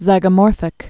(zīgə-môrfĭk, zĭgə-) also zy·go·mor·phous (-fəs)